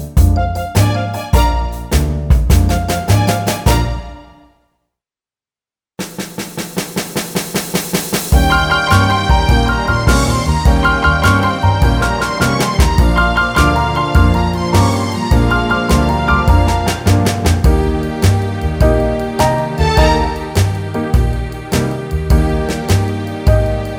Key of D Jazz / Swing 2:59 Buy £1.50